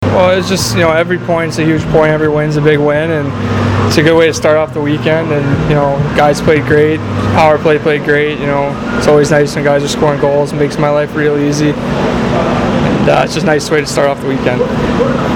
After the game I managed to catch up with Dean Evason, Filip Forsberg, and Scott Darling who gave their thoughts on the game.